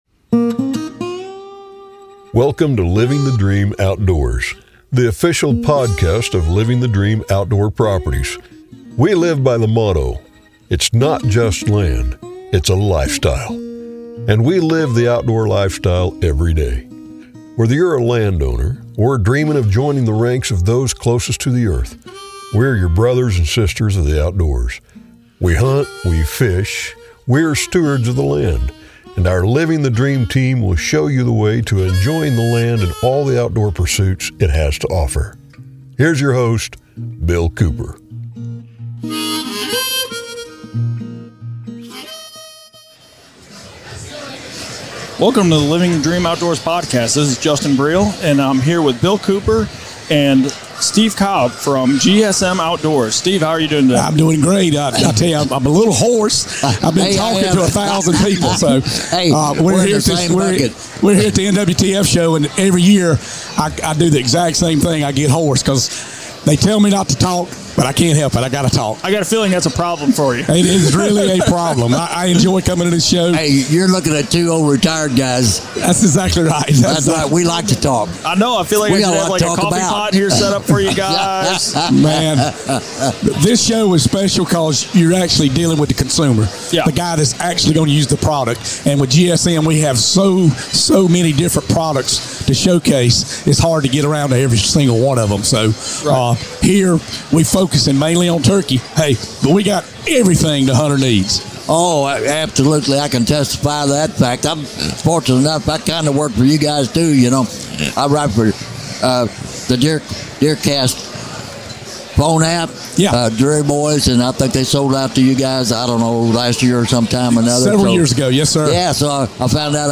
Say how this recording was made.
at the National Wild turkey Federation’s Annual convention in Nashville, Tennessee